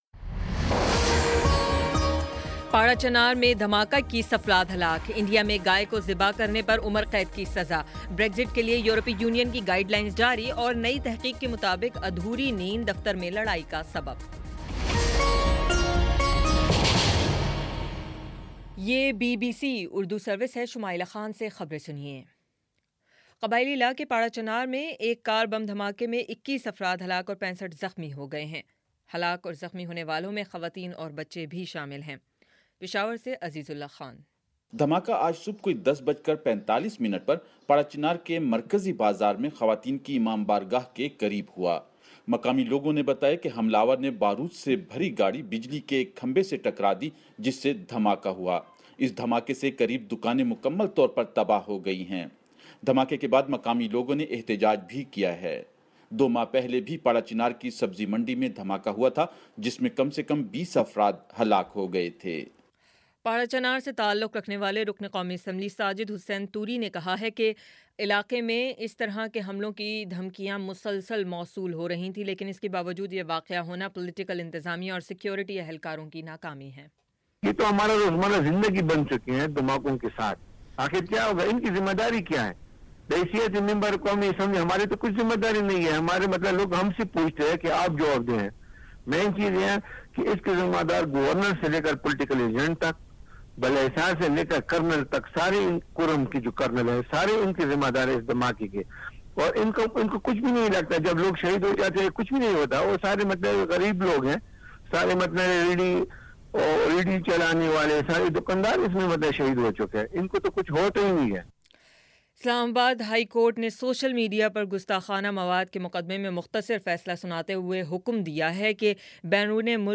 مارچ 31 : شام پانچ بجے کا نیوز بُلیٹن